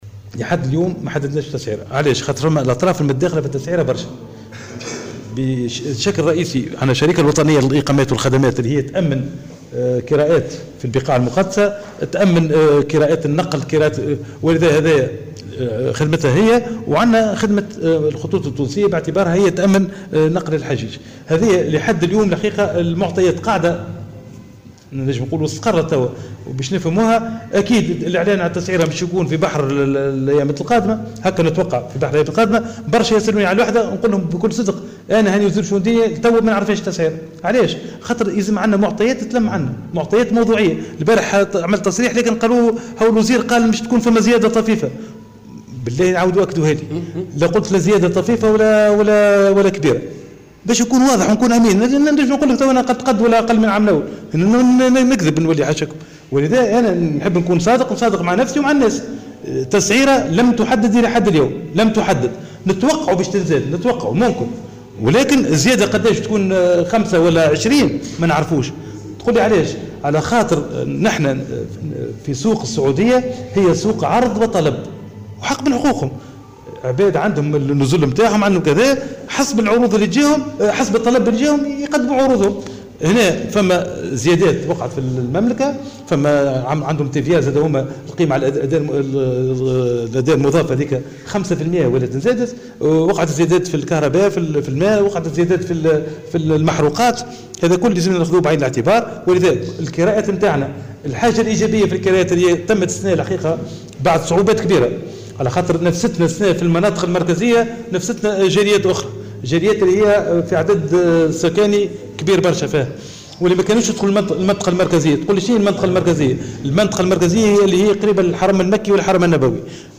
وأضاف في تصريح اليوم لمراسل "الجوهرة أف أم" على هامش زيارة أداها لولاية سيدي بوزيد، أنه من المنتظر الإعلان عن التسعيرة خلال الأيام القليلة القادمة.